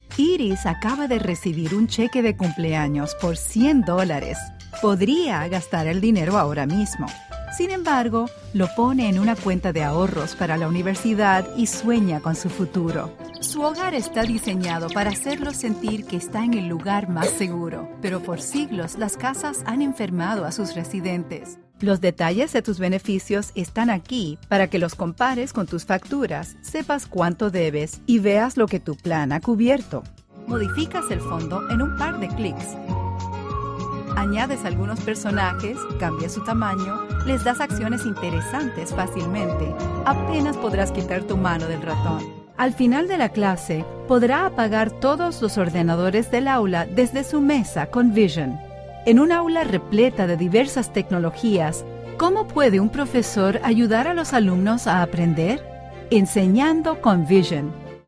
Erklärvideos
Meine Kunden beschreiben meine Stimme am besten als freundlich, spritzig, ausdrucksstark, angenehm, warm und enthusiastisch